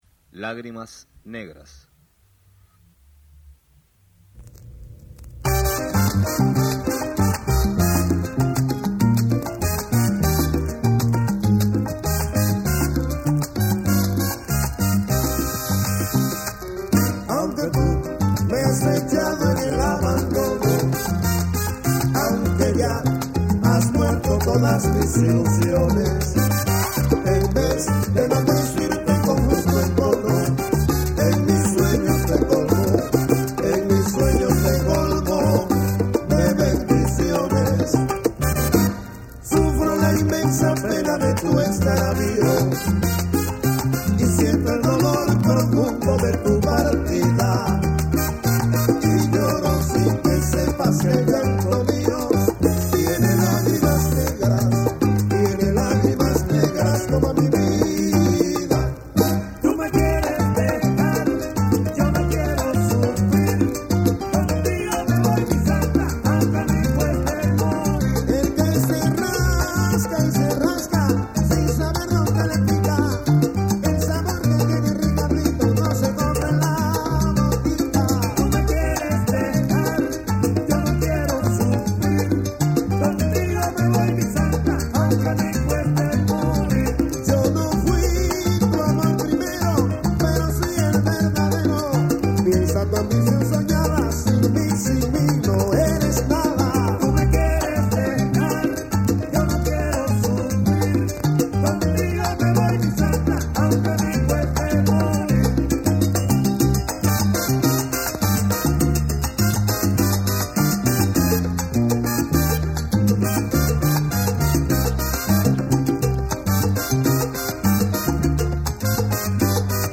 True_Cuban_Bass_Track9.mp3